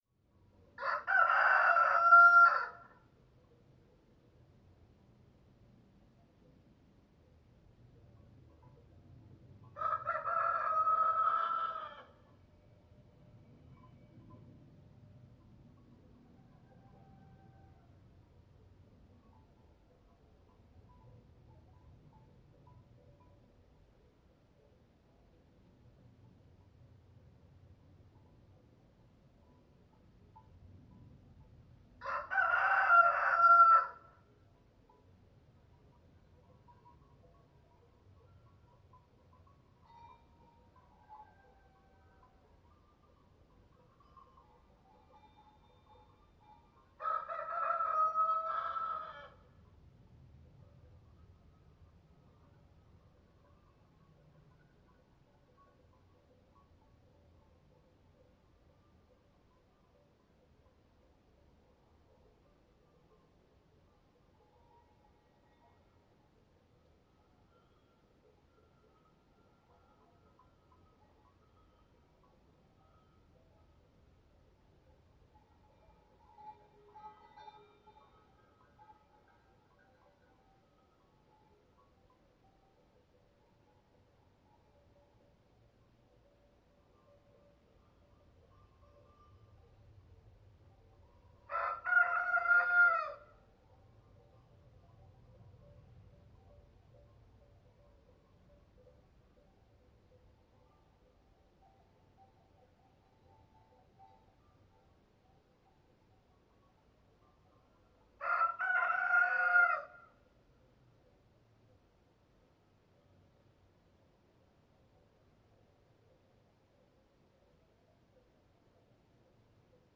دانلود صدای خروس برای بیدار باش با صدای زمینه گله گوسفندان از ساعد نیوز با لینک مستقیم و کیفیت بالا
جلوه های صوتی